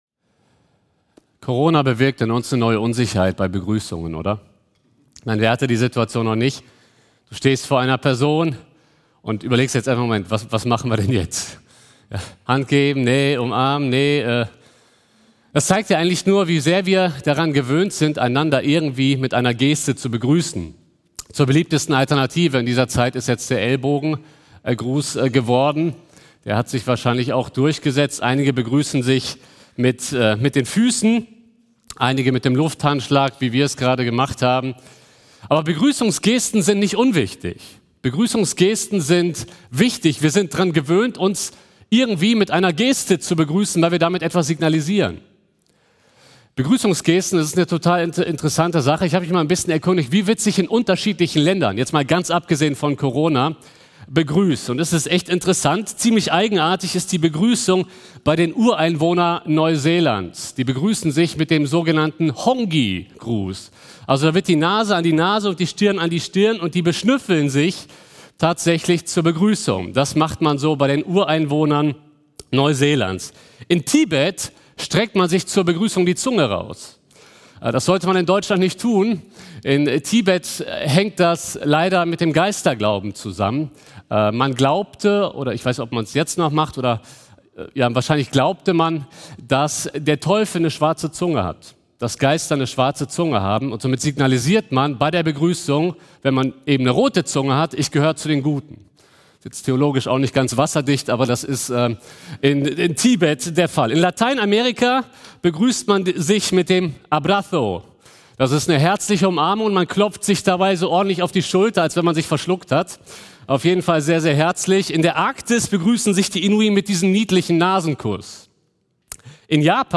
Juni 2020 Predigt-Reihe: Philipperbrief